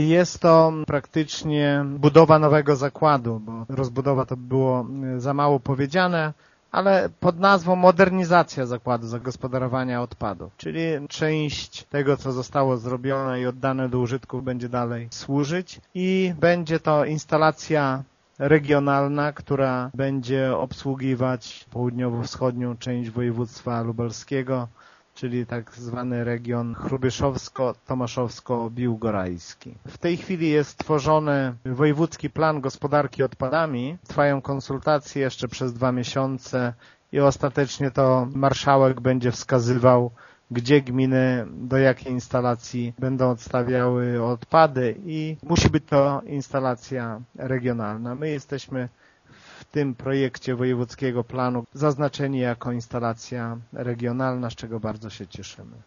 Wprawdzie formalnie w nazwie projektu jest mowa o modernizacji, w rzeczywistości zakres inwestycji będzie zdecydowanie większy – mówi Informacyjnej Agencji Samorządowej wójt Lech Szopiński: